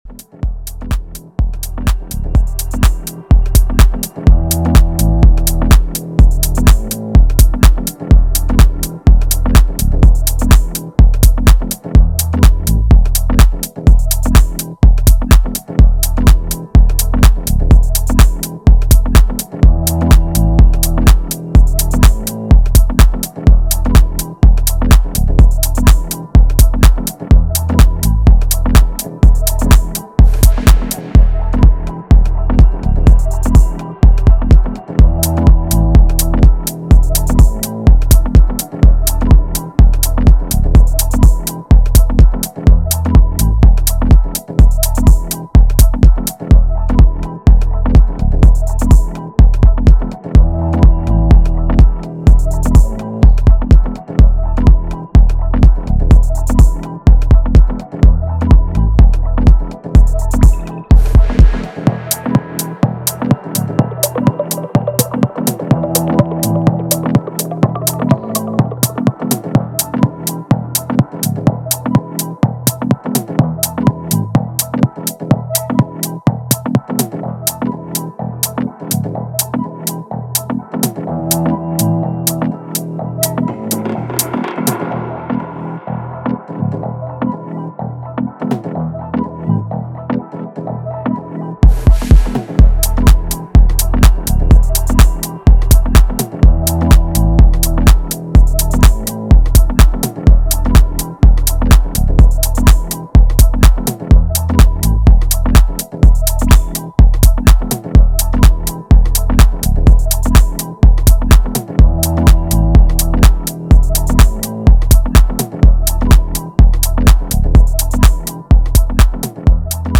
three track minimal groove EP